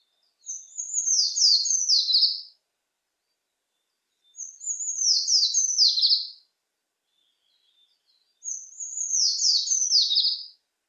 キバシリ｜日本の鳥百科｜サントリーの愛鳥活動
「日本の鳥百科」キバシリの紹介です（鳴き声あり）。